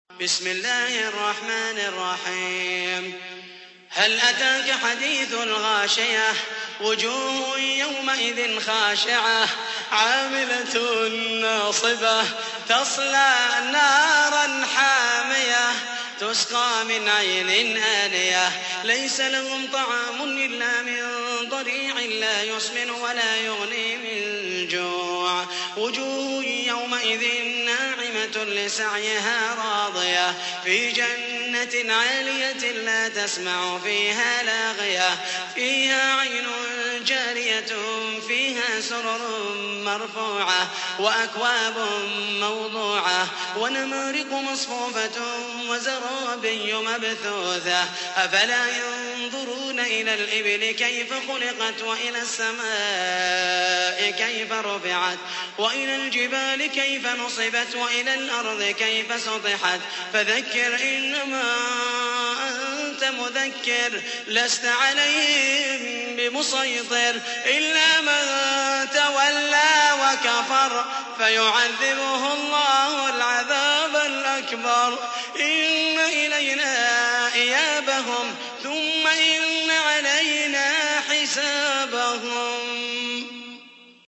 تحميل : 88. سورة الغاشية / القارئ محمد المحيسني / القرآن الكريم / موقع يا حسين